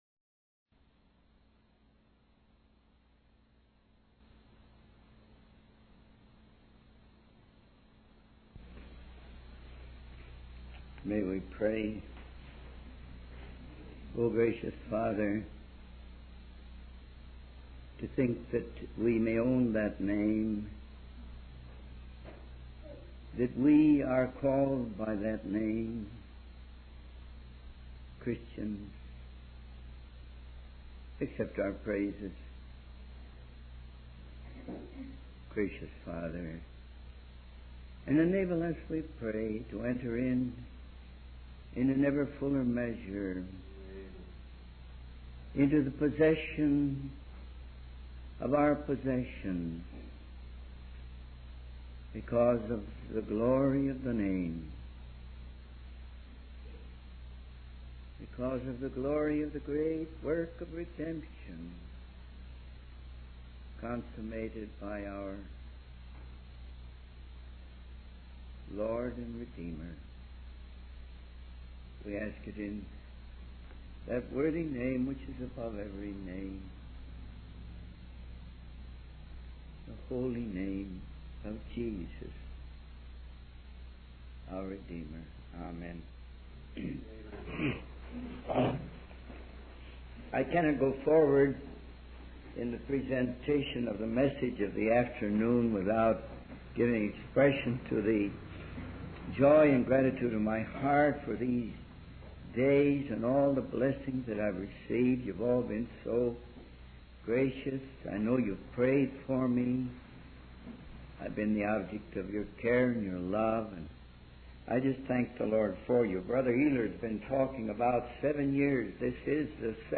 In this sermon, the preacher emphasizes the perfect standing that believers have before God through the sacrifice of Jesus Christ.